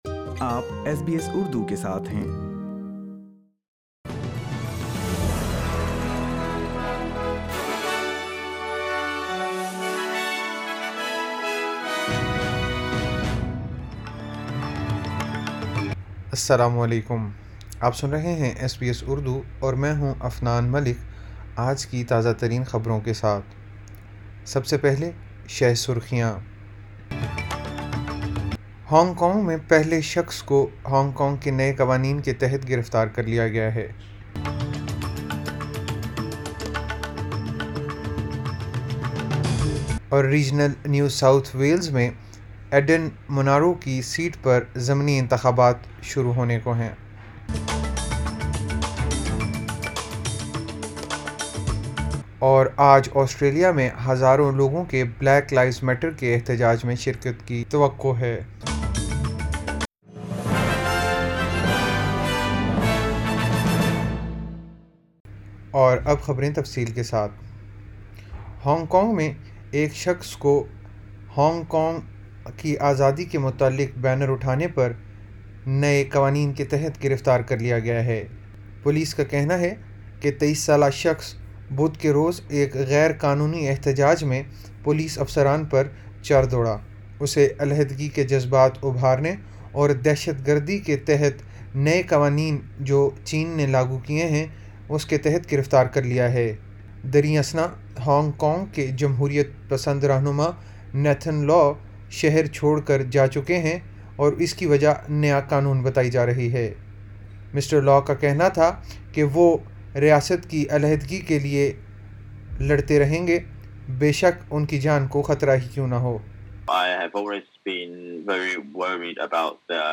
SBS Urdu News 04 July 2020